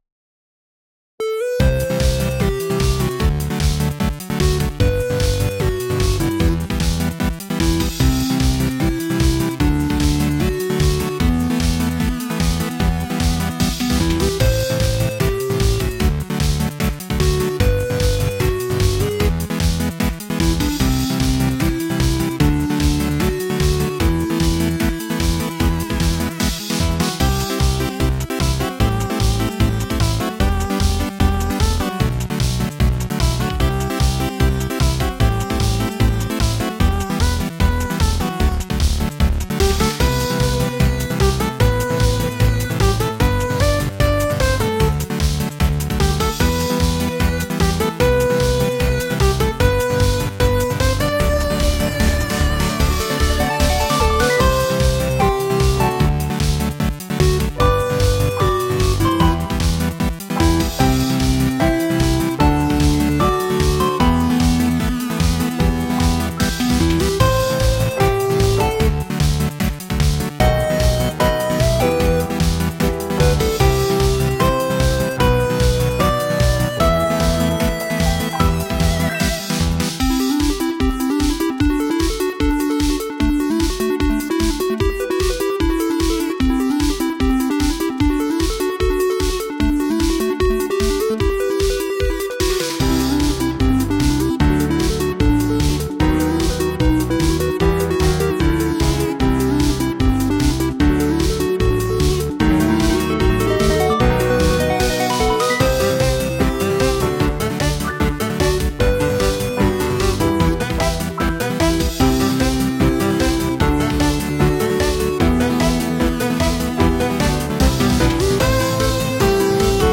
and was inspired by modern anime music.